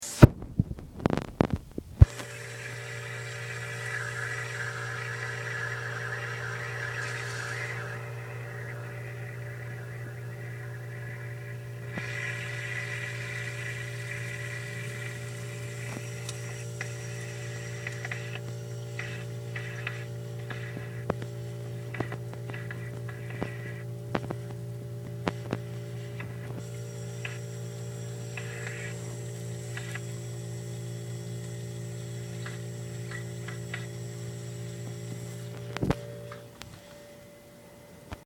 Downstairs bathroom
Sounds heard: Whirring of the bathroom fan, The toilet water flowing in the tank, the refrigerator door opening and closing, a pan being moved on the stove, the bathroom door being nudged